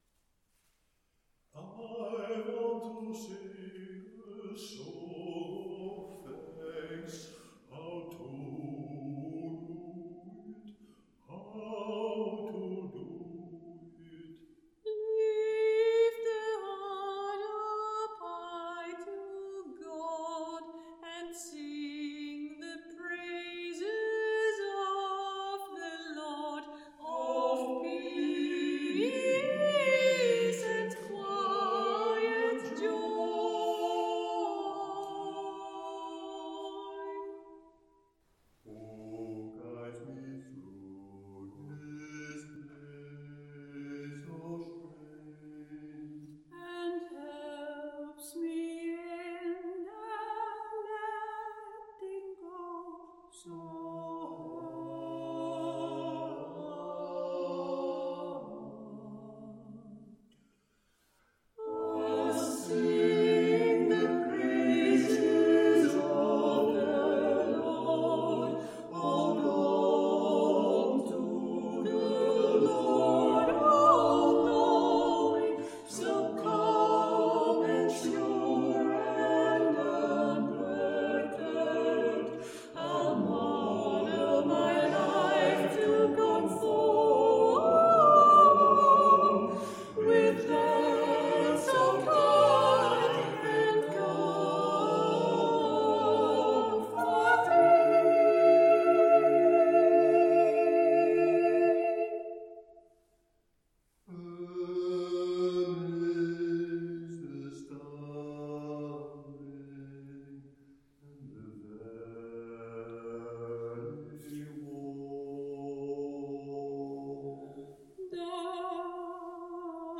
Soprano
Alto
Tenor
Bass